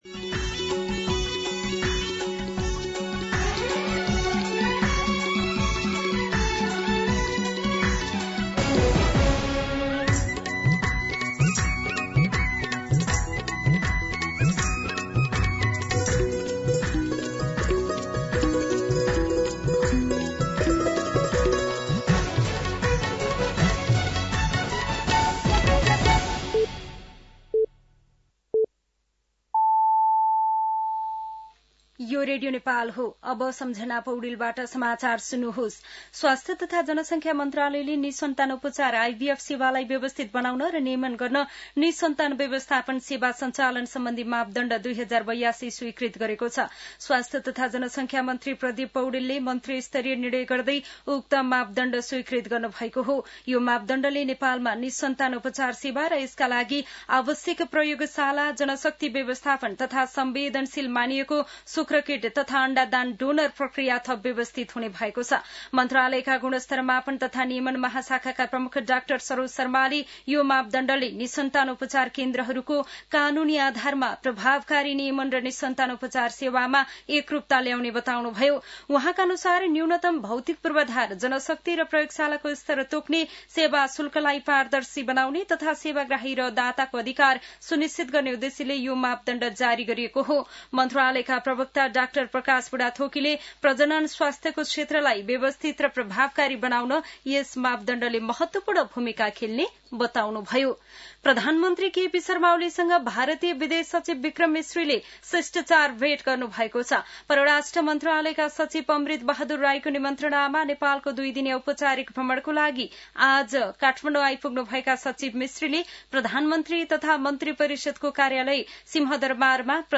दिउँसो १ बजेको नेपाली समाचार : १ भदौ , २०८२
1pm-News-05-1.mp3